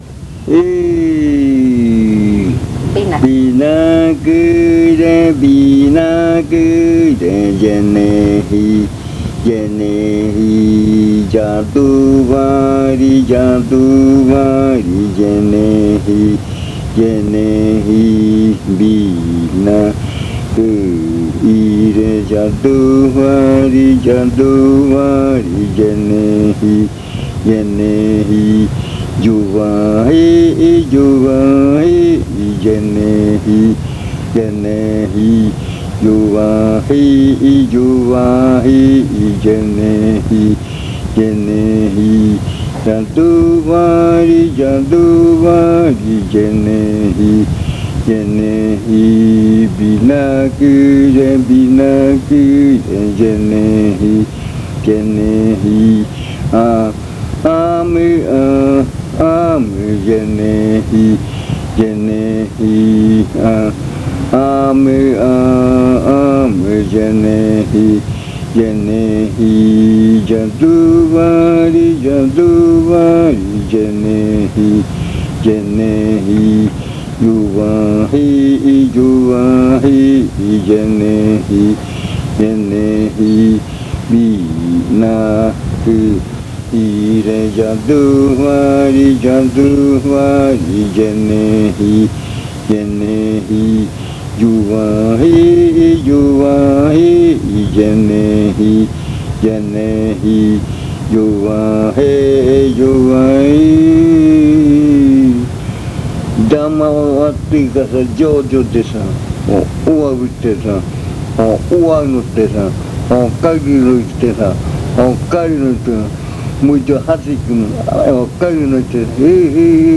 Leticia, Amazonas
Este canto hace parte de la colección de cantos del ritual Yuakɨ Murui-Muina
Cantos de yuakɨ